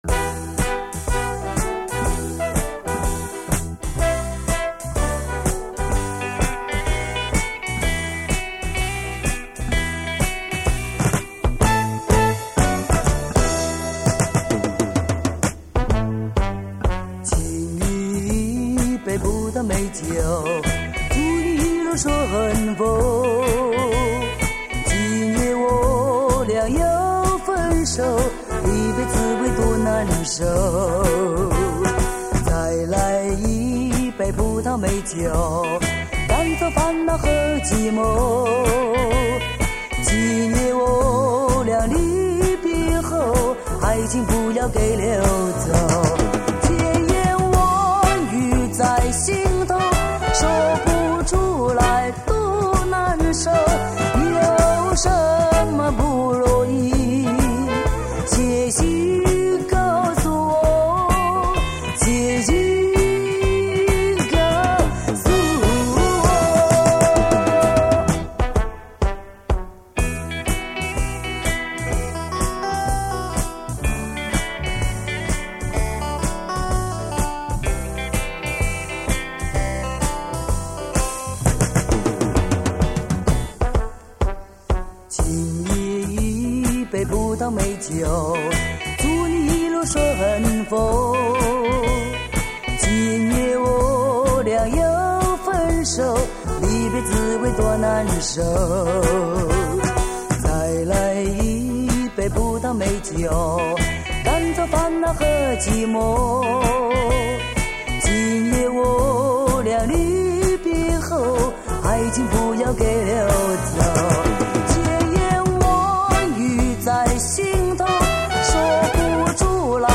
回味从小到大的磁性声音 惟有黑胶原版CD